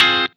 Track 08 - Guitar Stab OS 03.wav